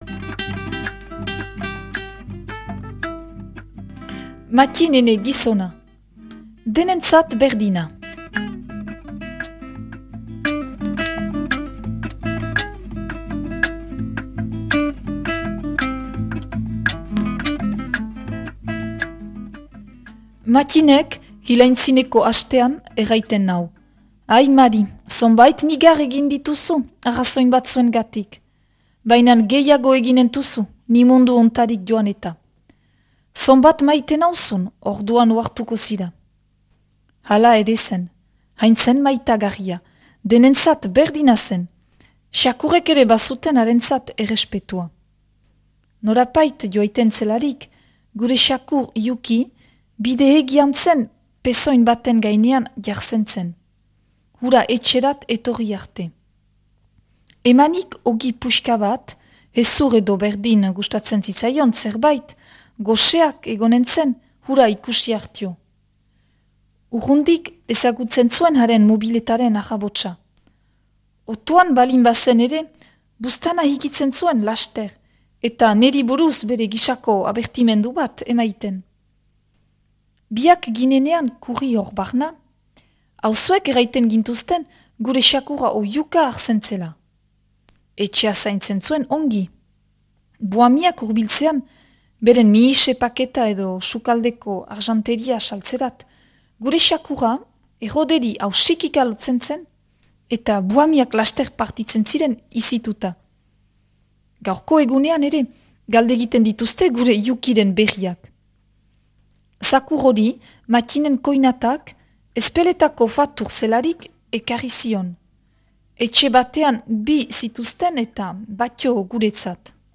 Gure artxiboetarik atera sail hau, Ahetzeko Mattin Trecu (1916-1981) bertsolariari eskainia.
proposatu irakurketa da.